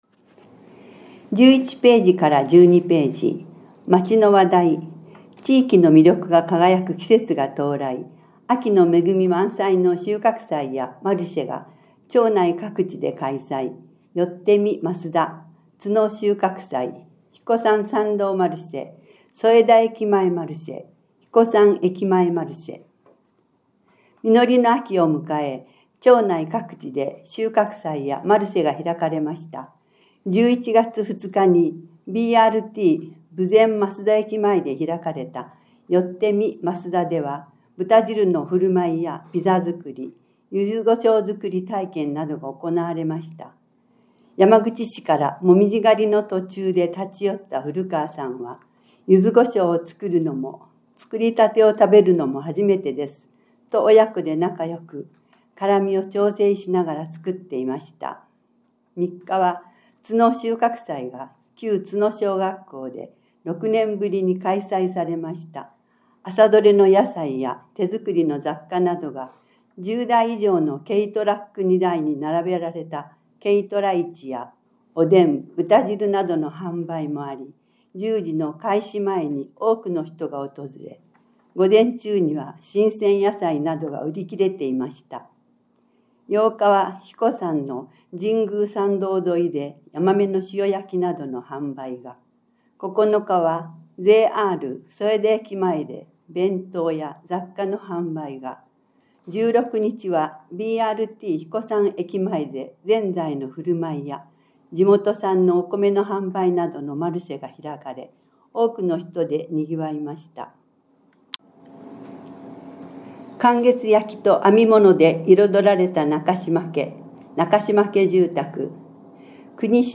目や手の障がいなどにより、広報そえだを読むことができない人に広報紙の内容をお伝えするため、広報そえだを音声化しました。